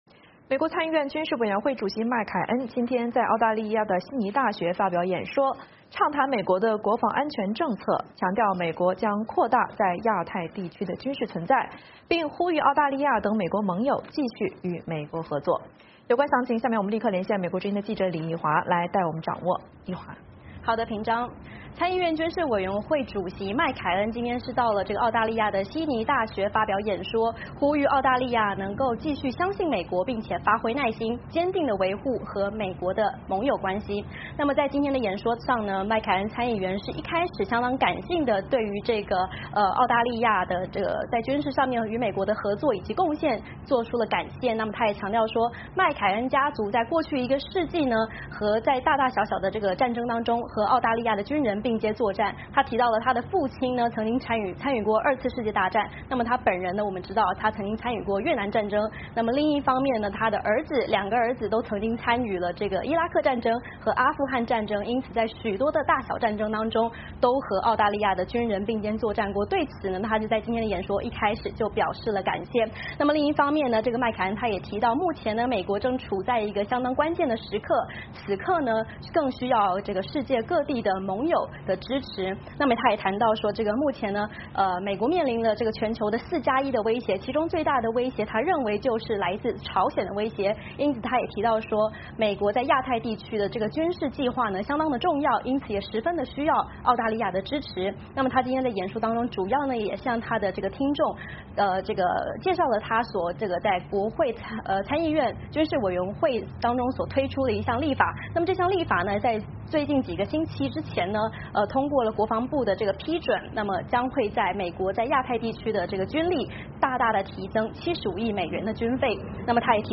VOA连线：麦凯恩悉尼发表演说 呼吁美国盟友继续合作